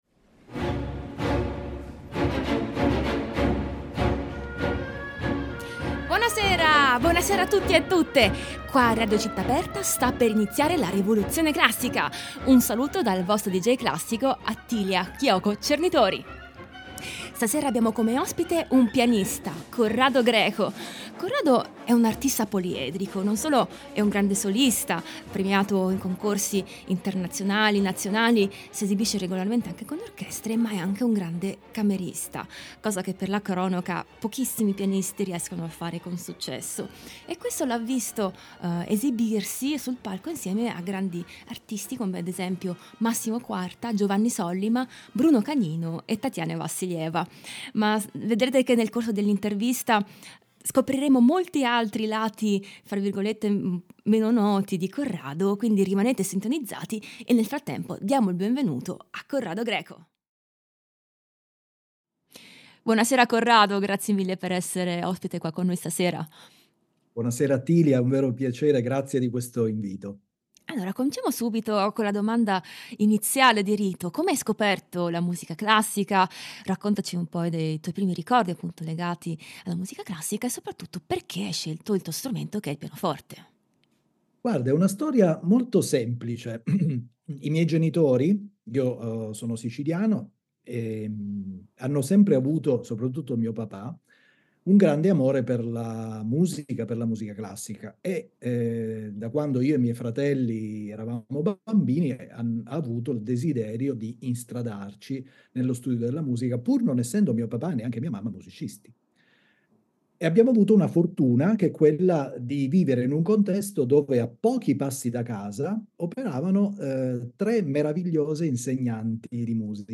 Ospite di questa puntata il pianista
voce recitante
pianoforte a quattro mani